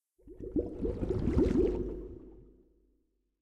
whirlpool_ambient4.ogg